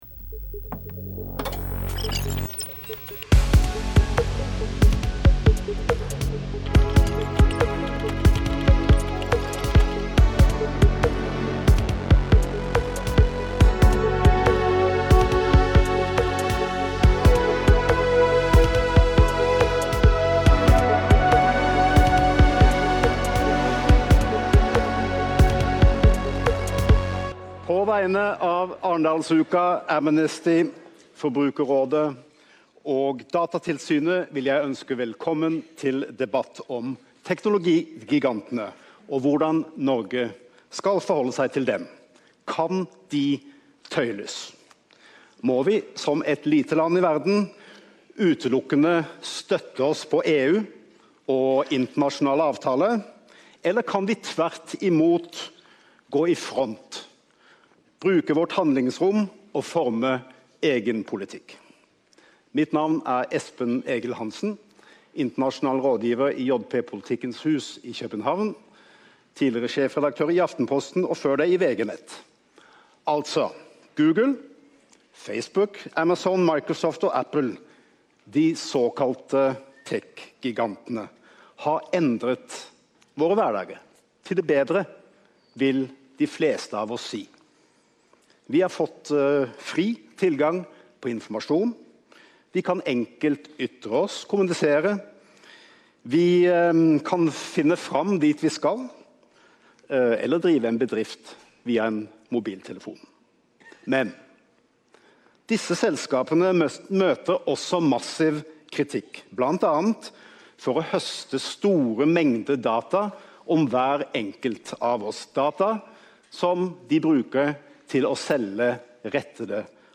I opptaket fra dette arrangementet får en litt innsikt i EUs prosesser fra en av de som har ledet forhandlingene.
Debatt mellom sentrale politikere og representanter fra teknologisektoren: Er det nok å følge etter EU, eller kan Norge ta lederskap?